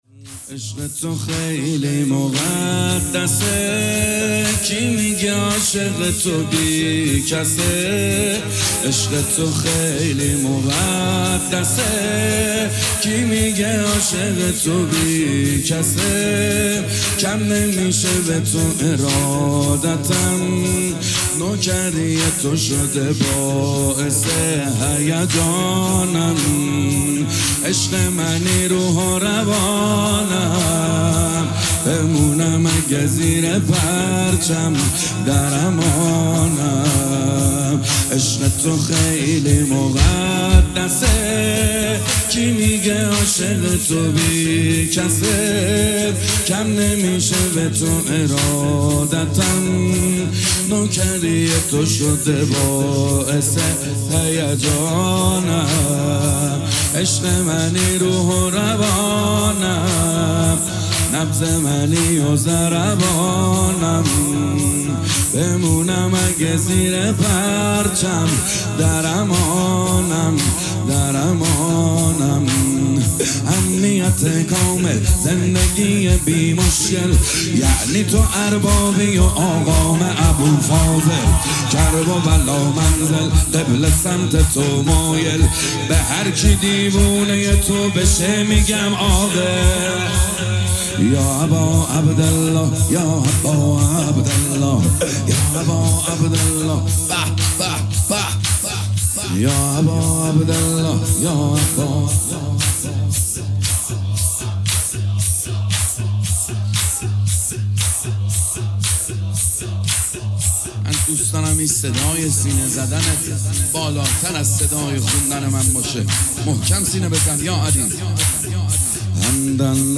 مداحی زمینه امام حسین